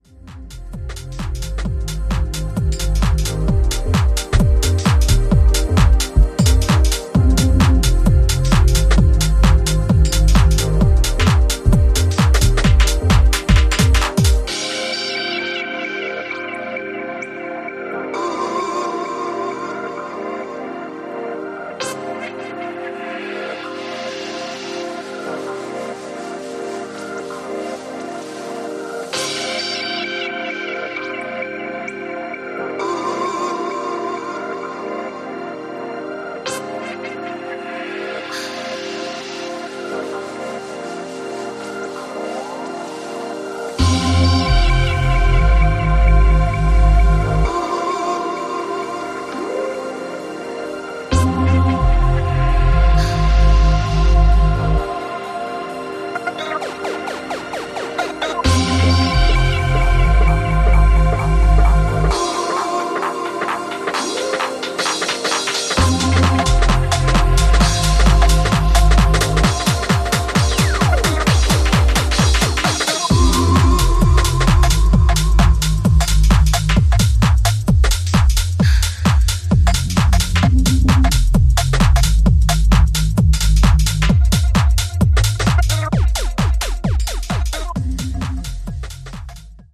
メランコリックなメロディーも爆発を手伝う新曲